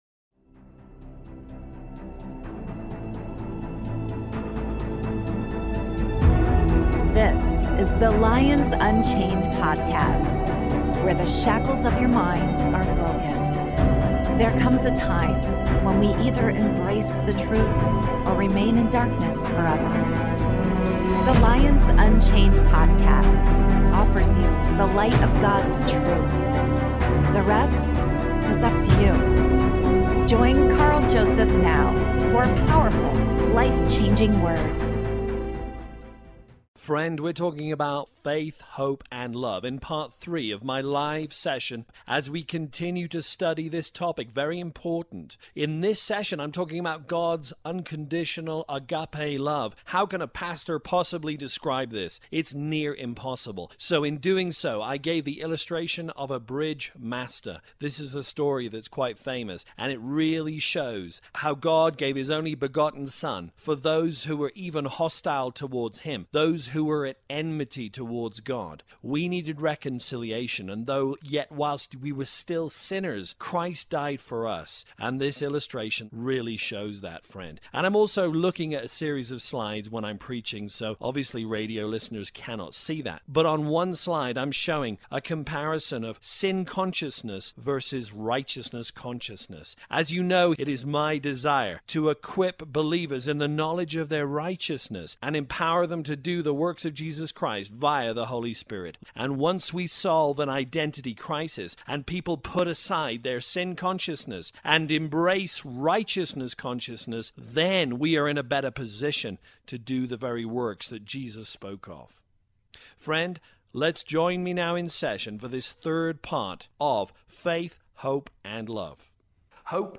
Faith, Hope & Love: Part 3 (LIVE)